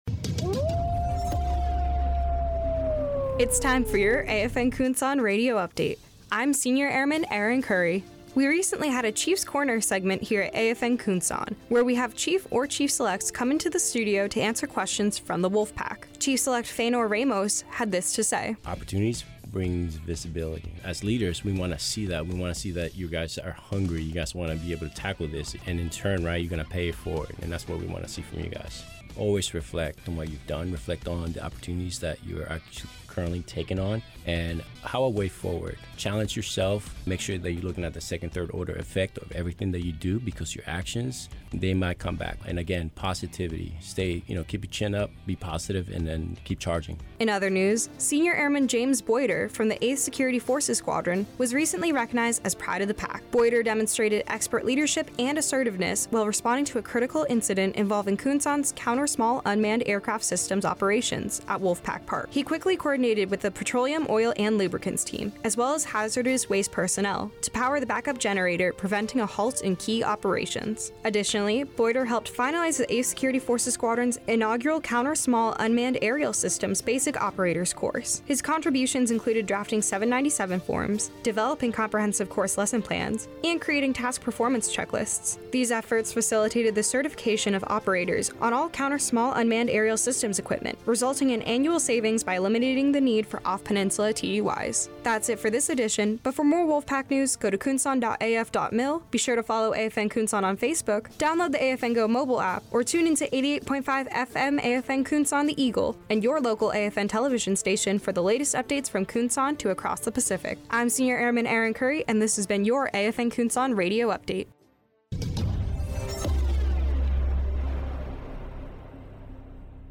This AFN Kunsan Radio Update covers July's Chief's Corner interview, a monthly reoccurring live radio interview, and the pride of the pack member that was recently recognized.